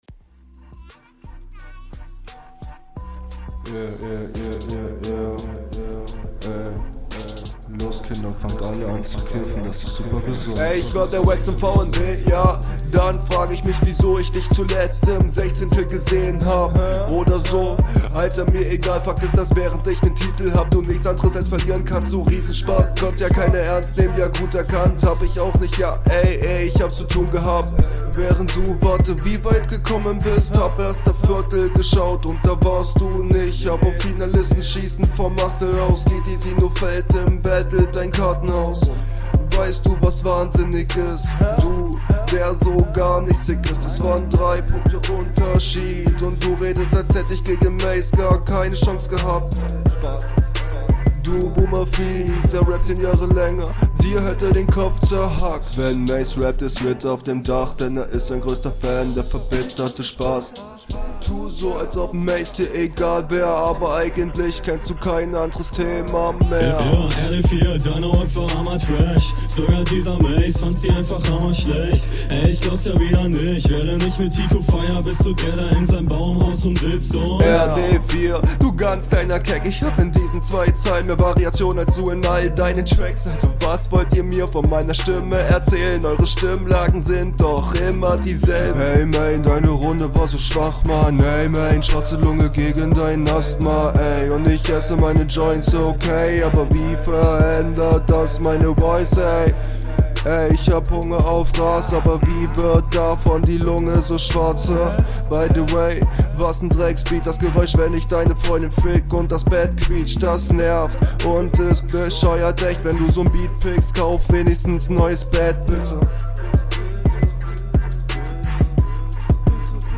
stolperst irgendwie an einigen stellen , klingt zu unsicher die stelle an der du varieierst …